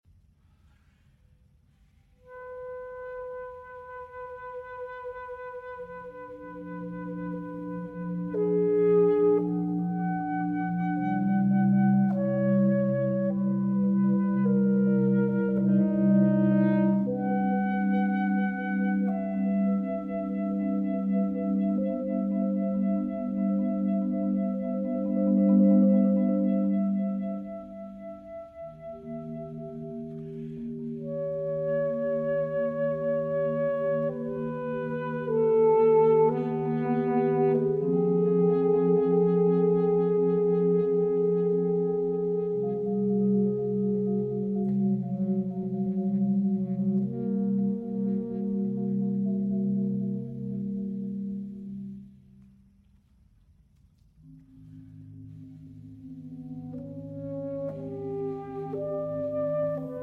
saxophone
marimba